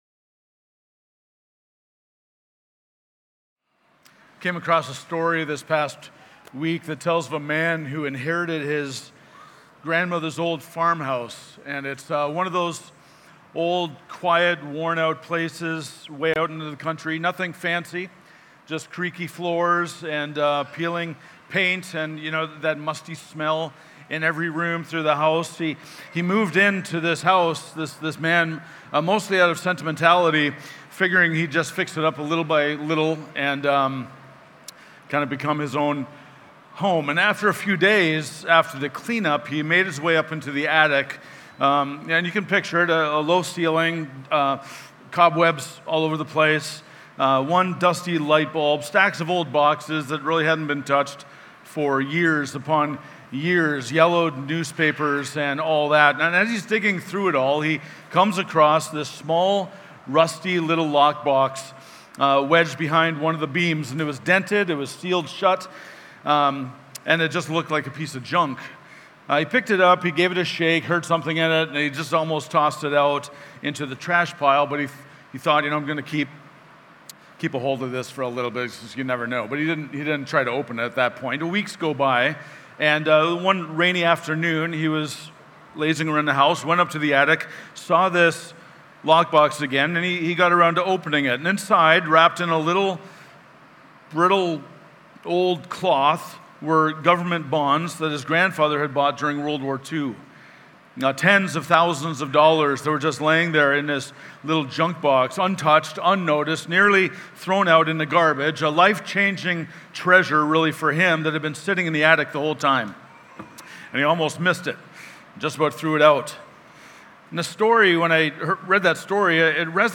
Messages given at the Sunday Morning Celebration Gathering of Sovereign Grace Church Dayton